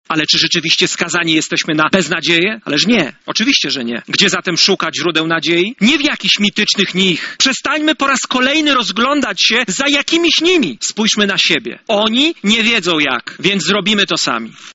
PiS choć obiecywał coś zupełnie innego, porzucił Polaków– mówi Hołownia: